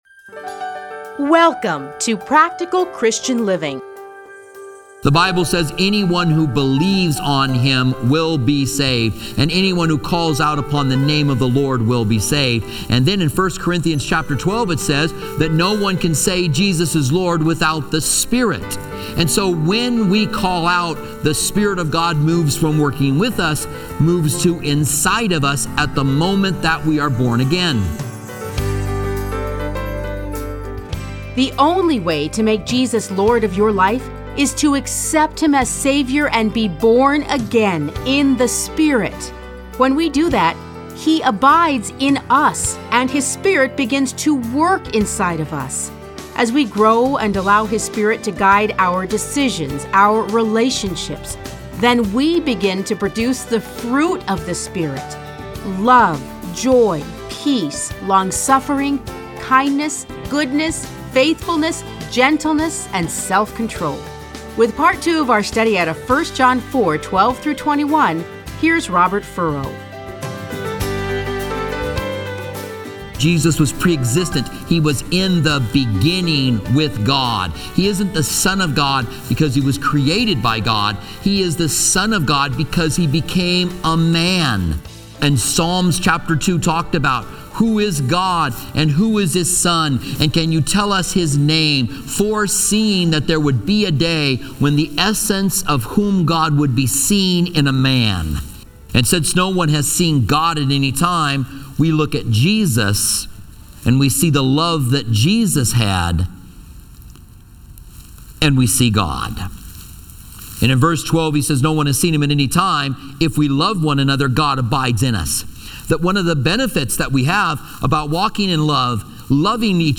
Listen to a teaching from 1 John 4:12-21.